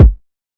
Lunch77 Kick 6.wav